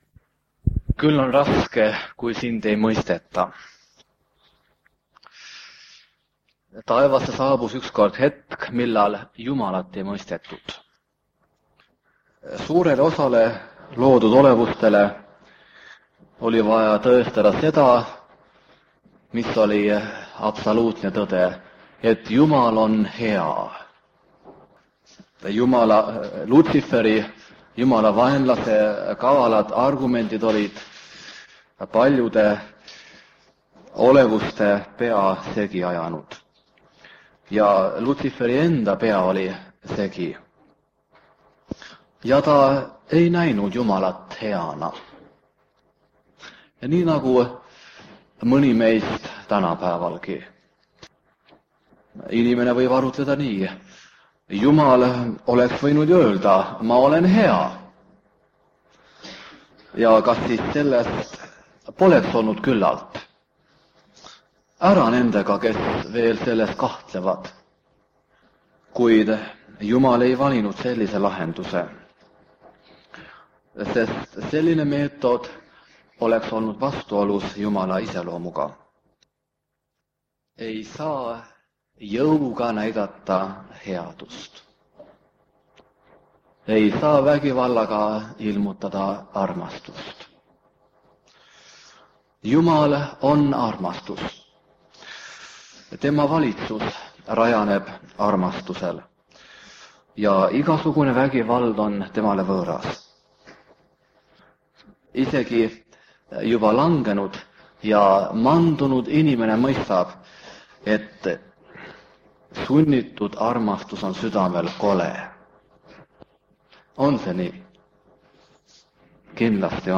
Kõne vanalt lintmaki lindilt 1977 aastast. Peale kõne on ka mõned muusikalised ettekanded.